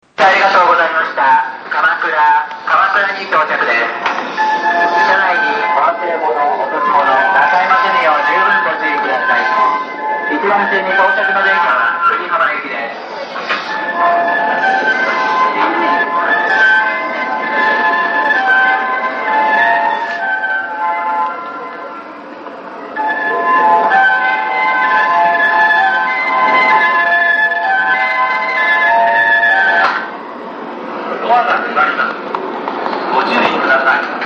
手持ち録音のため音量にご注意ください。
また、テープレコーダーで収録した古い音声のため、聞こえにくい部分がありますがご了承ください。